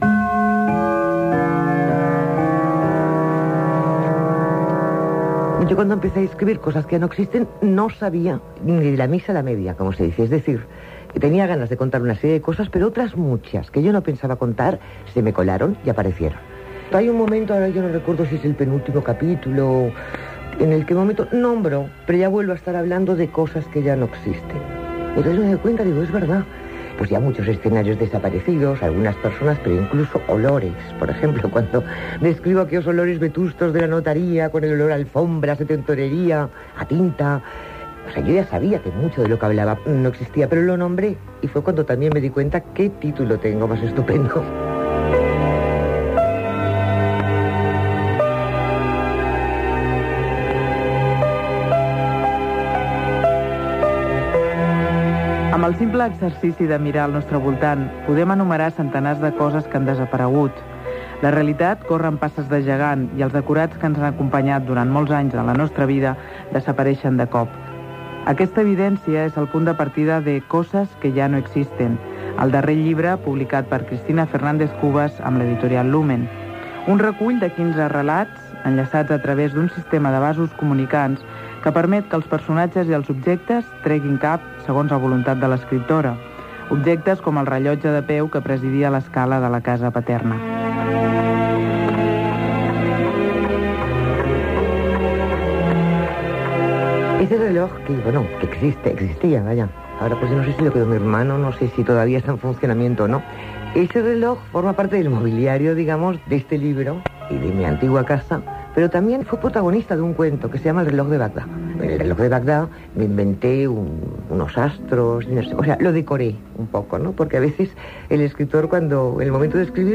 Entrevista a Cristina Fernández Cubas quan publicava el llibre "Cosas que ya no existen"
Info-entreteniment